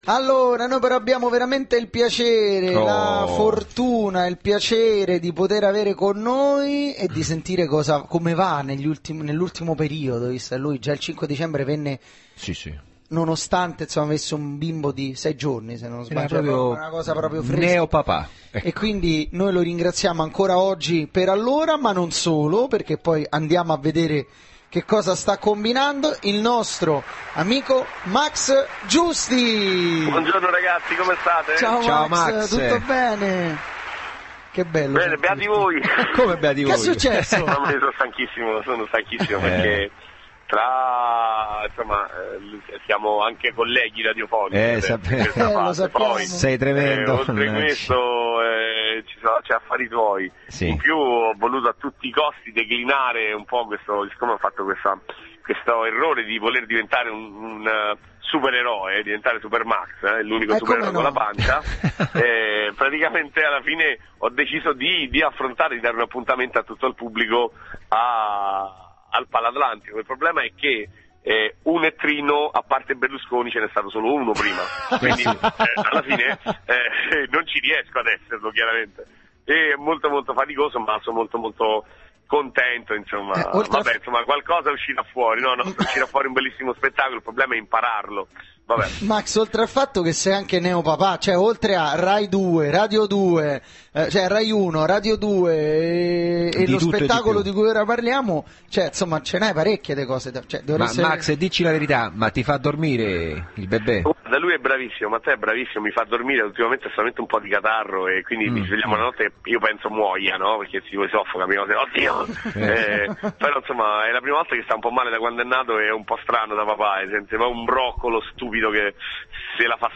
Intervento telefonico di Max Giusti del 23 marzo 2010